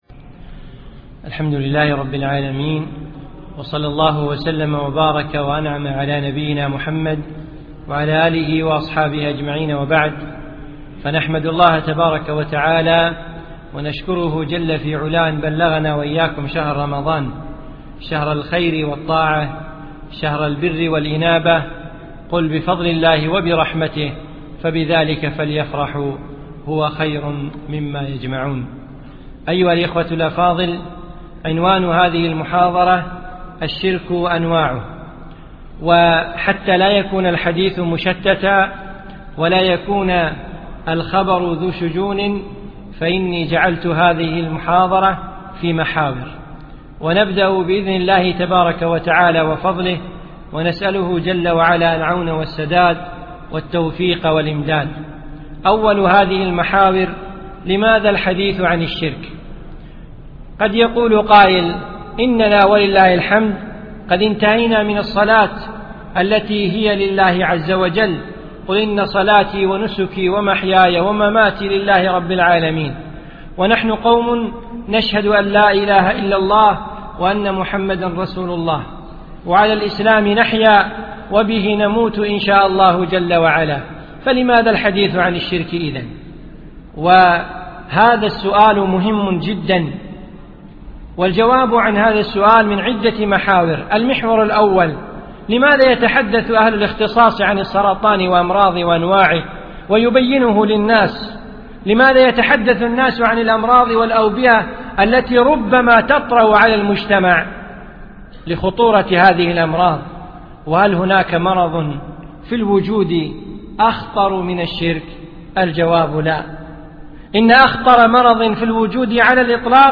أقيمت المحاضرة في دولة الإمارات